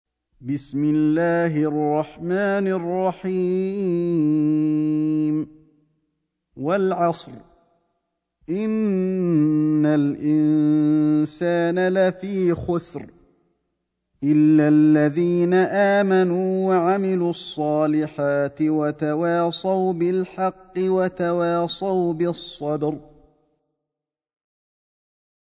سورة العصر > مصحف الشيخ علي الحذيفي ( رواية شعبة عن عاصم ) > المصحف - تلاوات الحرمين